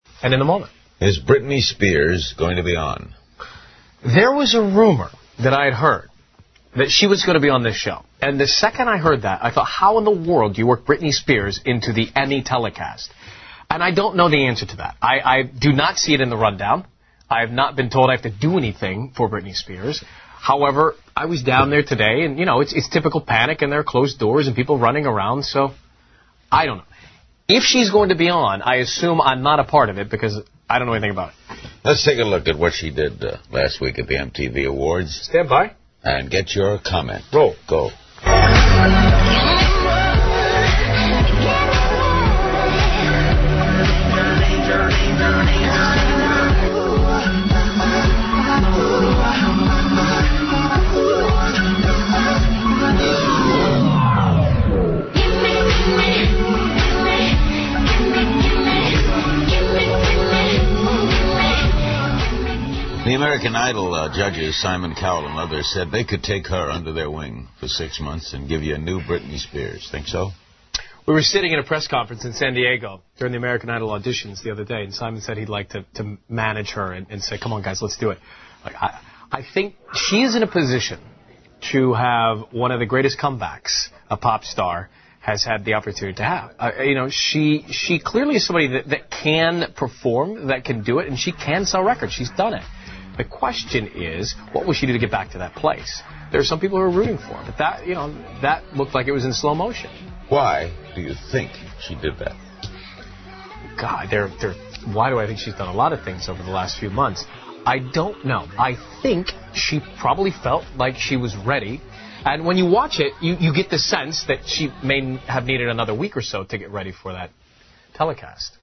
访谈录 Interview 2007-09-19&09-20, VMA上的布兰妮 听力文件下载—在线英语听力室